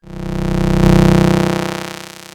ihob/Assets/Extensions/RetroGamesSoundFX/Hum/Hum25.wav at master
Hum25.wav